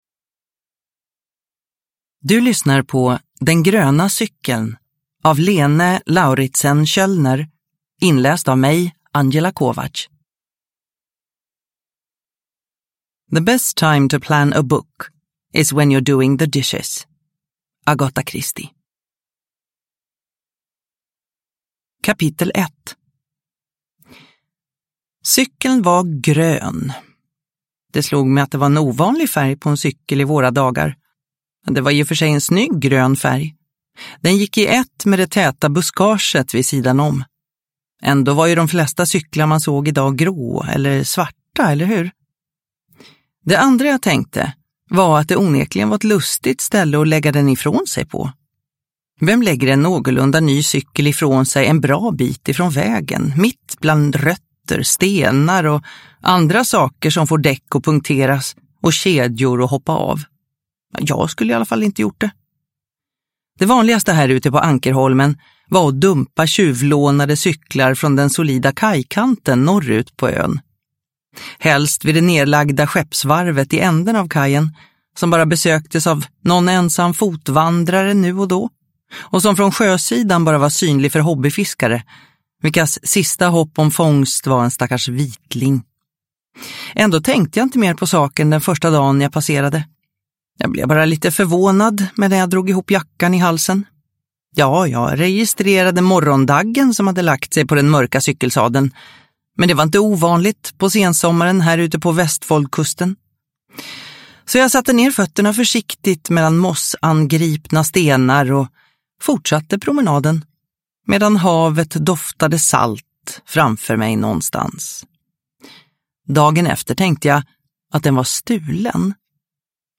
Den gröna cykeln (ljudbok) av Lene Lauritsen Kjølner